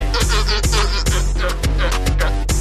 Sea Lion Sound Meme Mp3 Sound Button - Free Download & Play